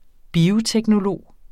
Udtale [ ˈbiːo- ]